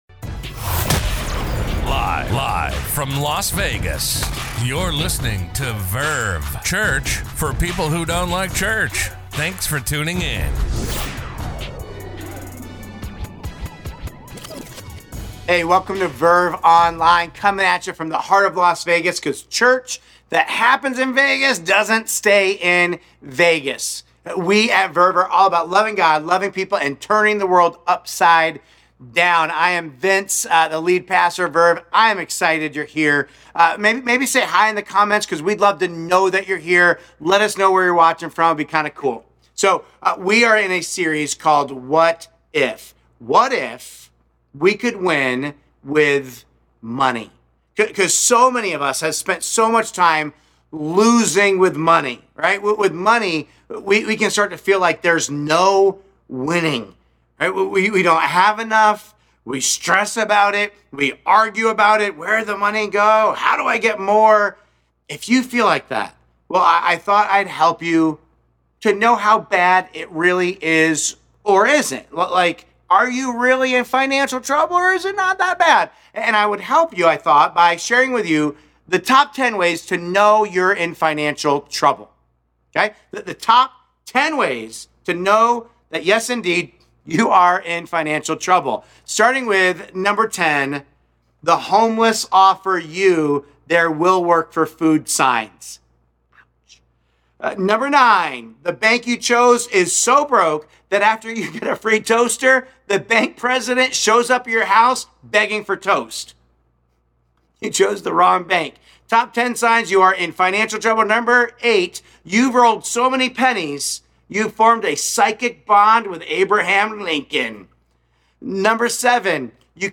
A message from the series "What If?."